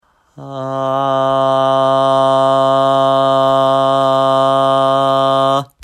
次に、息を吐く後半からハーアーと徐々に声を出していきます。
ハーーーーアーーーー